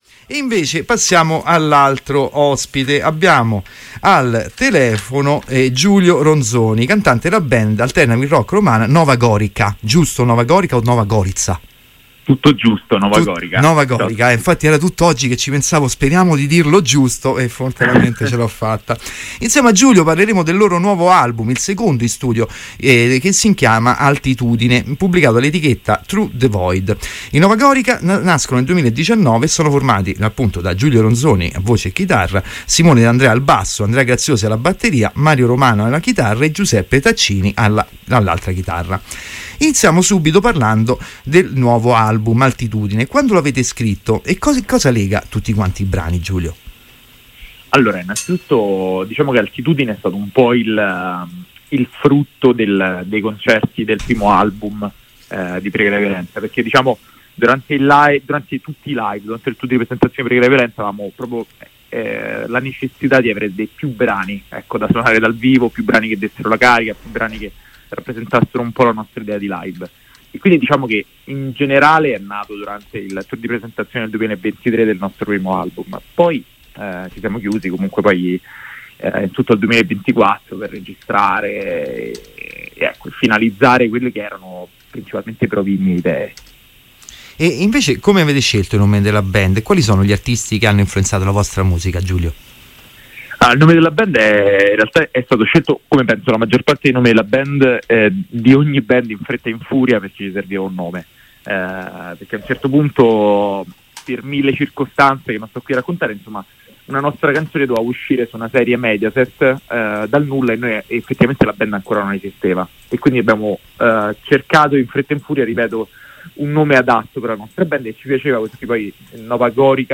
All'interno della puntata numero 12 di MeltingPot ho avuto il piacere di conoscere e intervistare telefonicamente
Intervista-Novagorica-26marzo.mp3